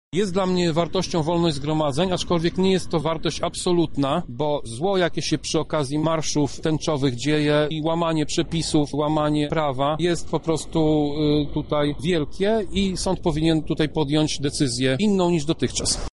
Takie wydarzenia stanowią zagrożenie dla moralności – mówi Tomasz Pitucha, radny PiS oraz inicjator zbiórki podpisów.